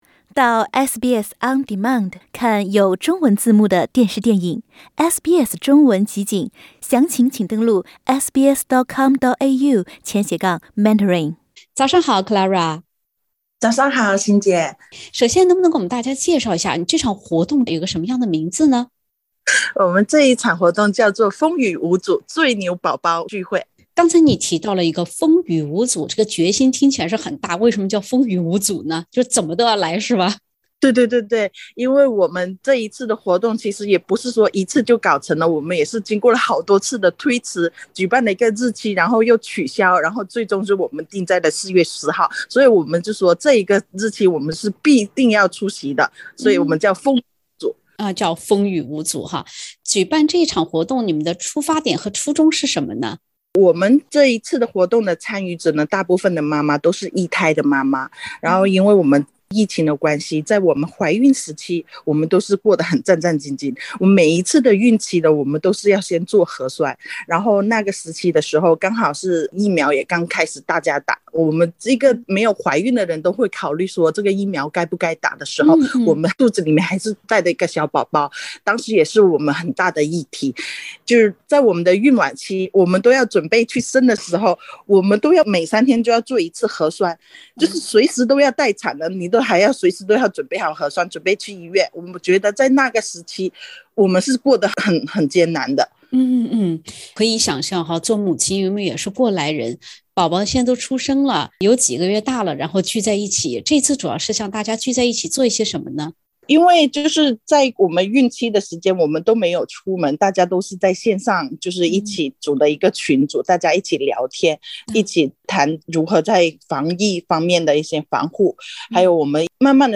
在疫情期间孕育出生的一群小婴儿，在爸爸妈妈的陪伴下盛装出席了自己人生的第一次线下社交活动。（点击封面图片，收听完整采访）